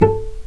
vc_pz-A4-pp.AIF